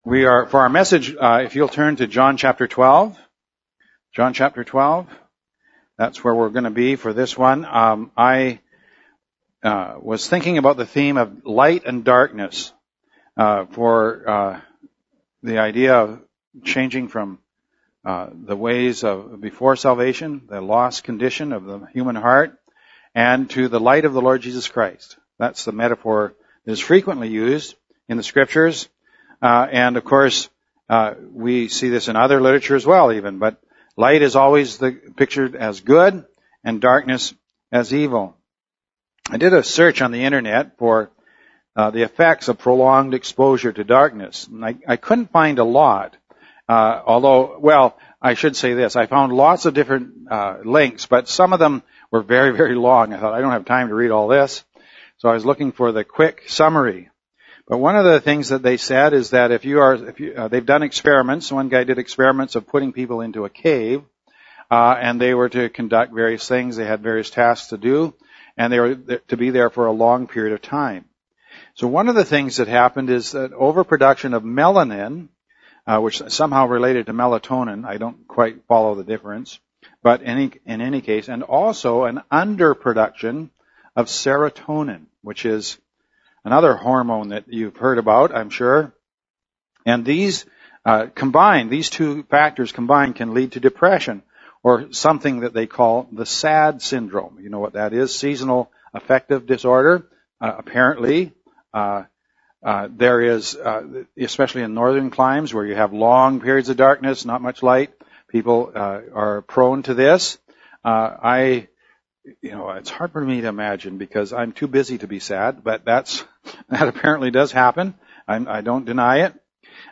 Jn 12.46 – For a baptism service, we look into the change that salvation brings, from darkness to light, as someone come from the old ways to the life that is in Christ.